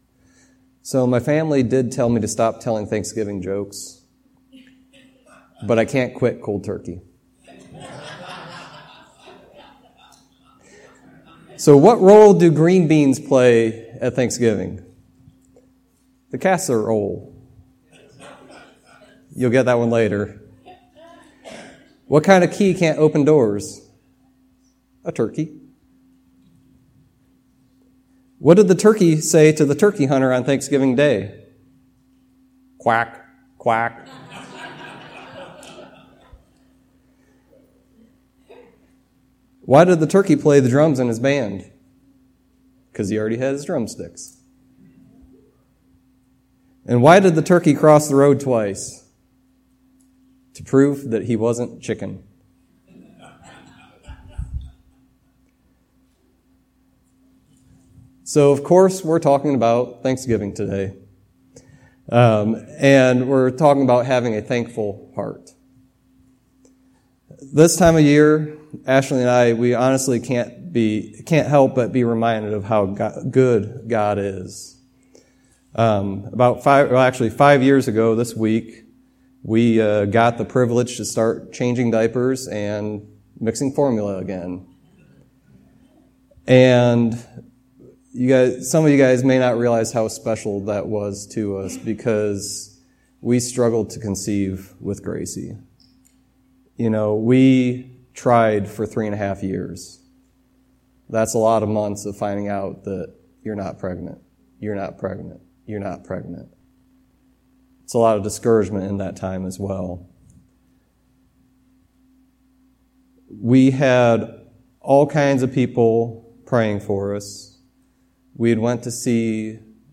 Psalm 100:1-5 Service Type: Sunday Teaching Its important to keep a thankful heart.